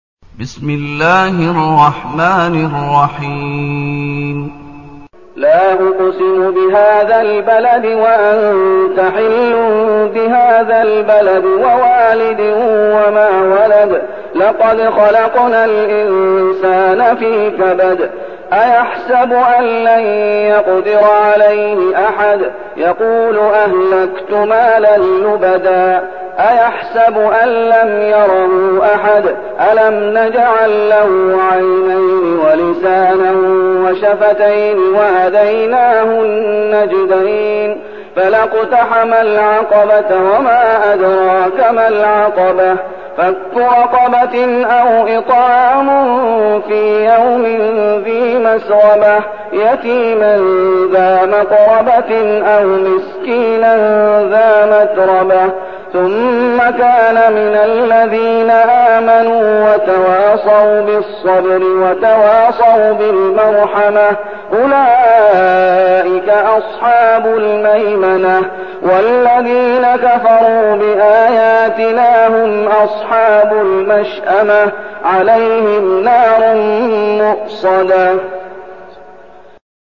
المكان: المسجد النبوي الشيخ: فضيلة الشيخ محمد أيوب فضيلة الشيخ محمد أيوب البلد